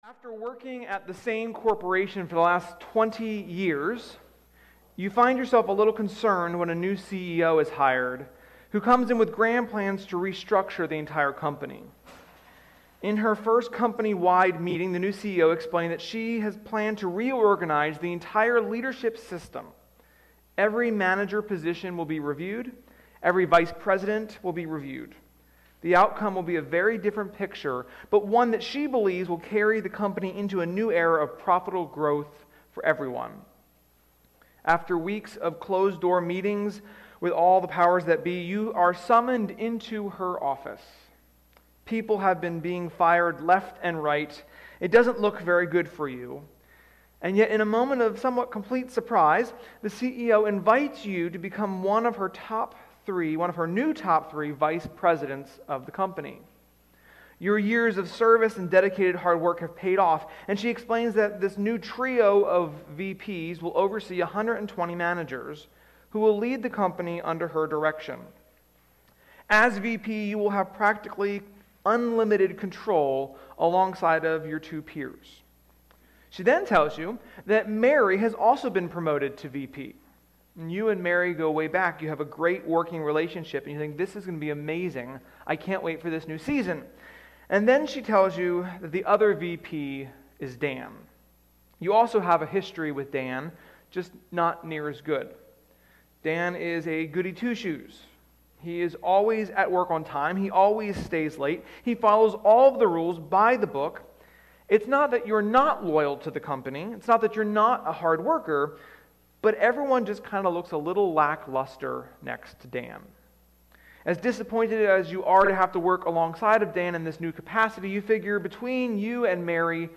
Sermon-7.18.21.mp3